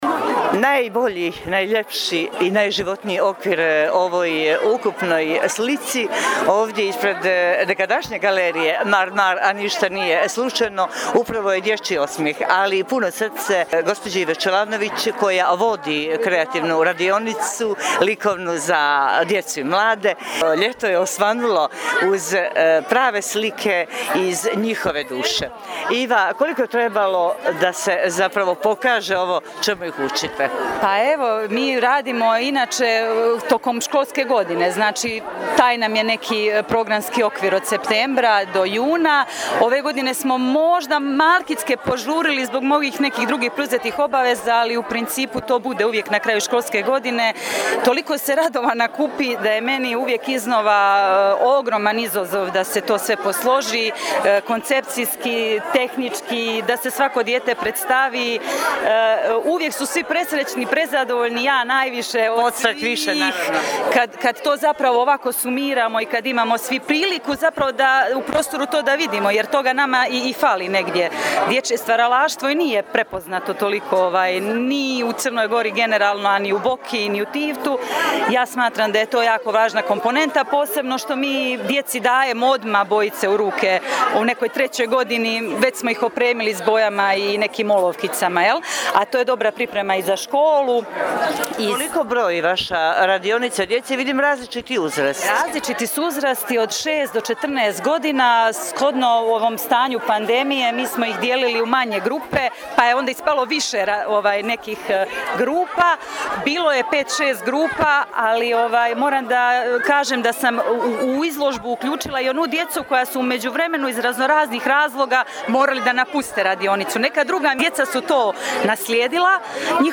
Sa-izložbe.mp3